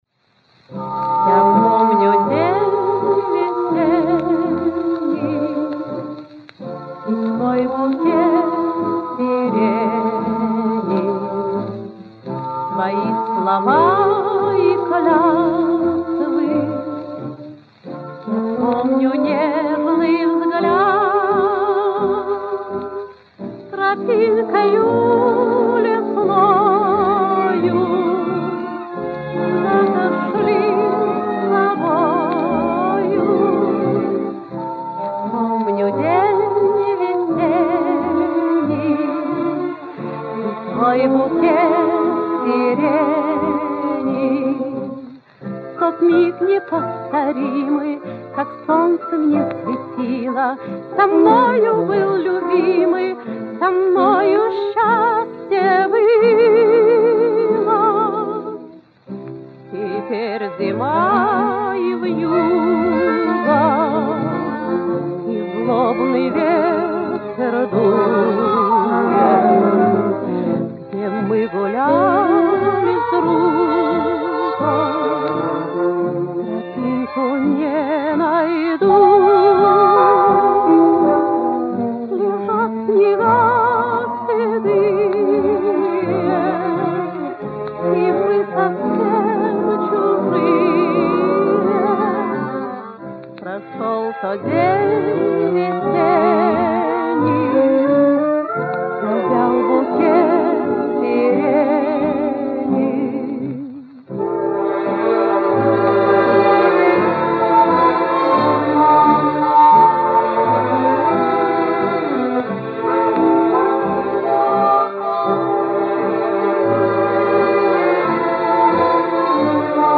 Каталожная категория: Меццо-сопрано с оркестром |
Жанр: Песня
Место записи:    Ленинград |